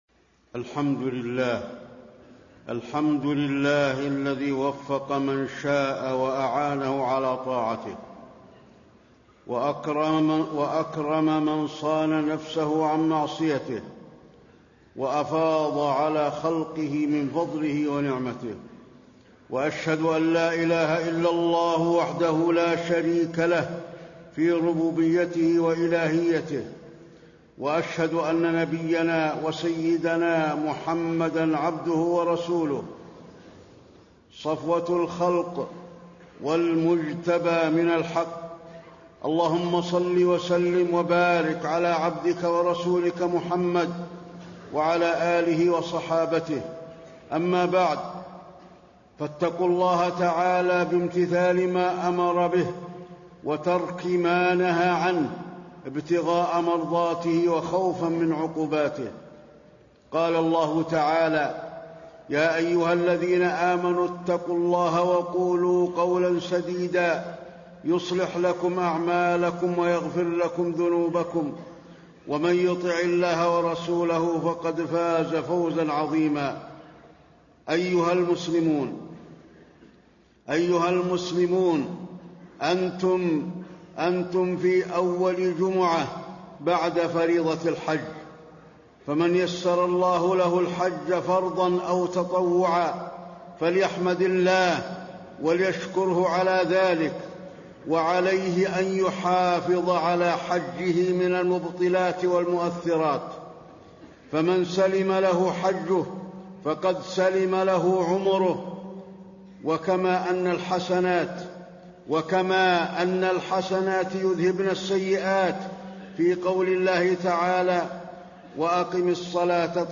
تاريخ النشر ١٧ ذو الحجة ١٤٣٣ هـ المكان: المسجد النبوي الشيخ: فضيلة الشيخ د. علي بن عبدالرحمن الحذيفي فضيلة الشيخ د. علي بن عبدالرحمن الحذيفي المصائب والأمراض بقدر الله تعالى